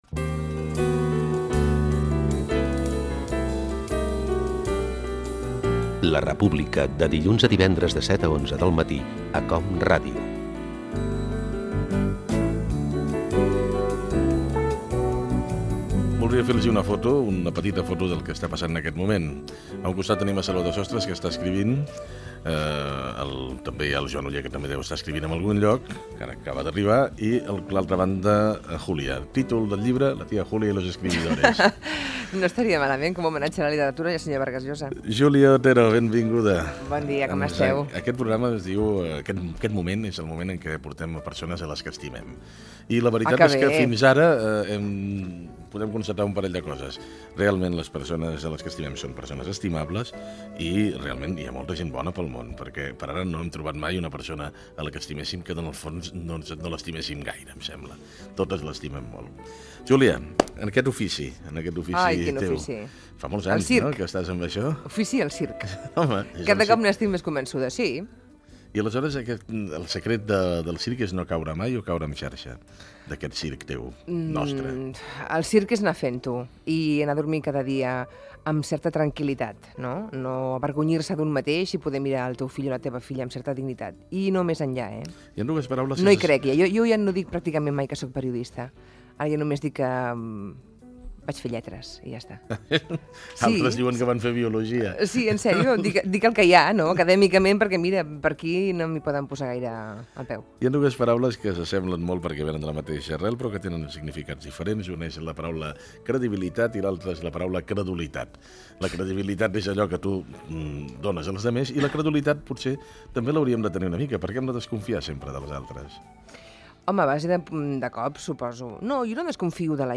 Indicatiu del programa, entrevista a la periodista Júlia Otero. Perfil del personatge, per Salvador Sostres.
Info-entreteniment